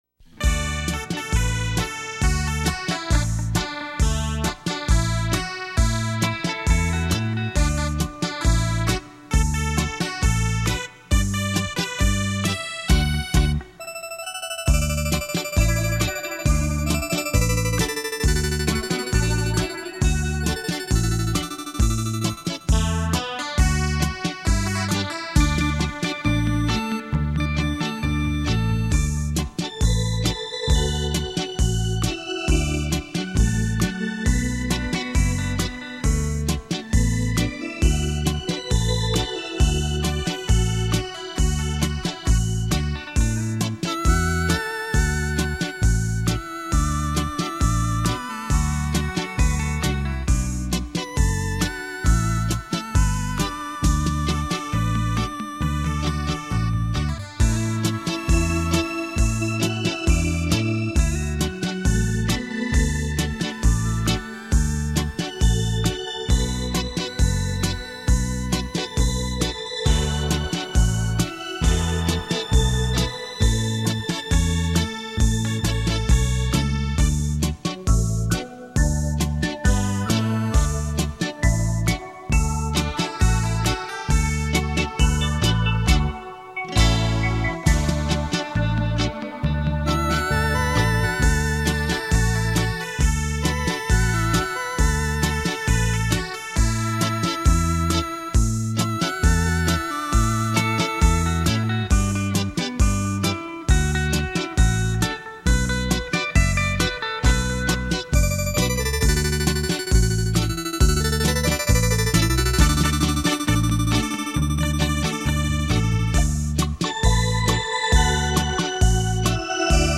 双电子琴特殊演奏
电子琴诠经典 表现另一种风情